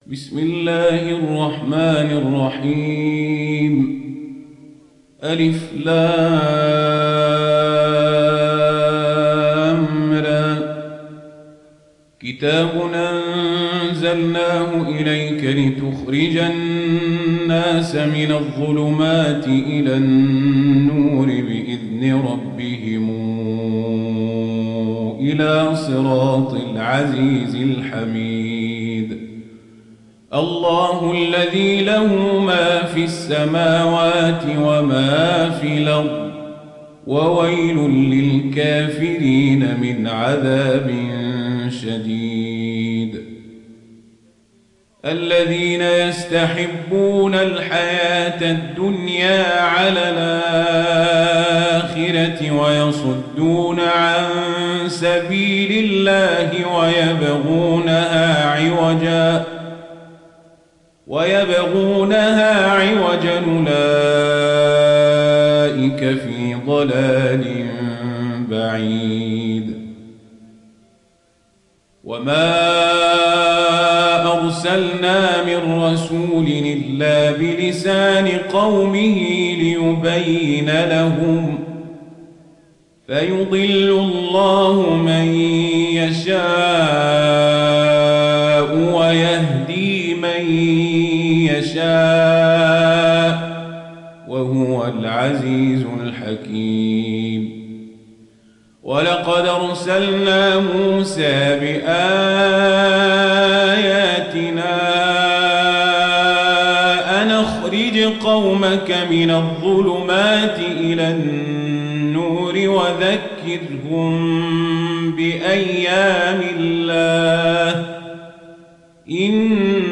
دانلود سوره إبراهيم mp3 عمر القزابري روایت ورش از نافع, قرآن را دانلود کنید و گوش کن mp3 ، لینک مستقیم کامل